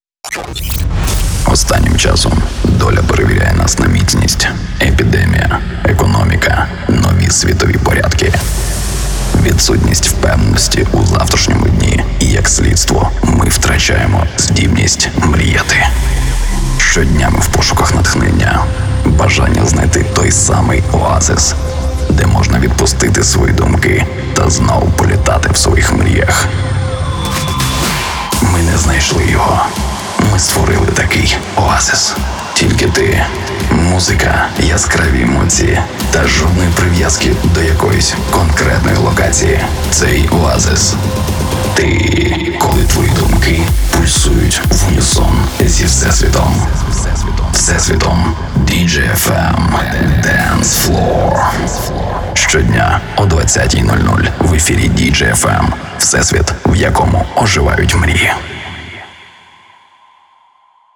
Начитую рекламні ролики.
Теги: Announcer, Audio_Production, Host Of Programs
DJFM_DANCEFLOORE_INTRO.wav